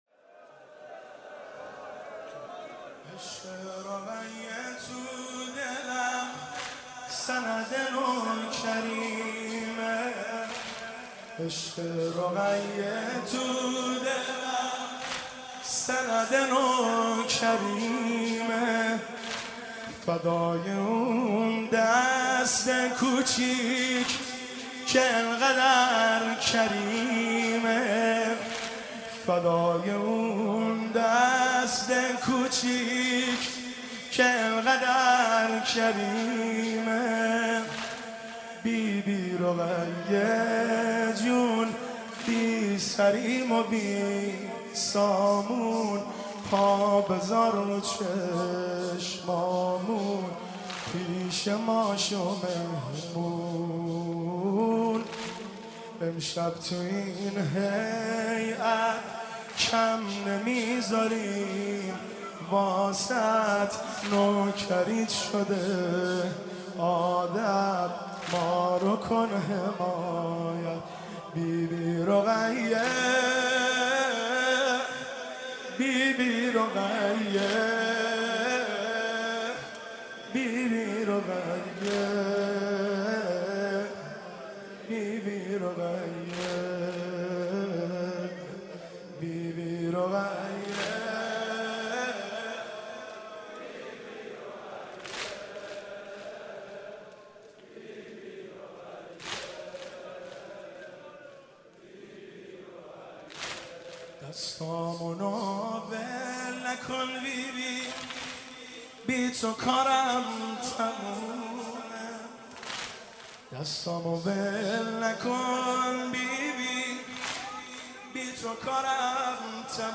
مداحی عشق رقیه تو دلم(واحد)
شب چهارم محرم 1392
هیئت خادم الرضا(ع) قم